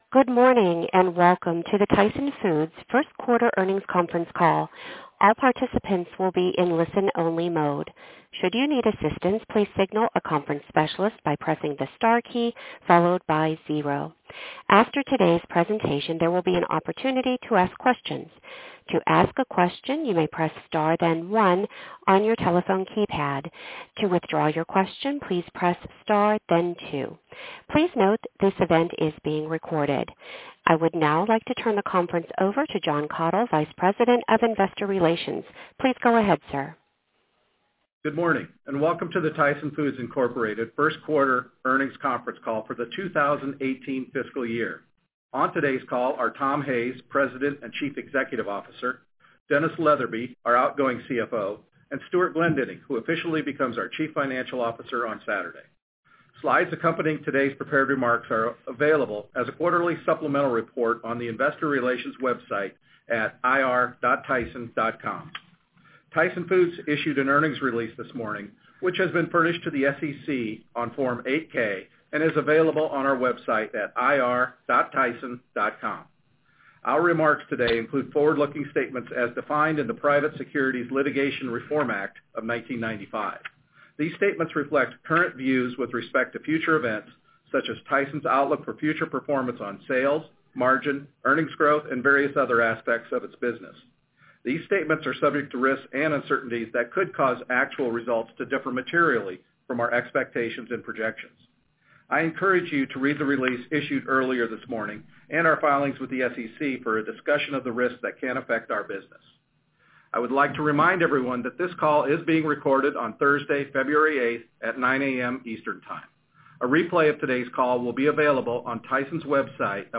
Tyson Foods Inc. - Q1 2018 Tyson Foods Earnings Conference Call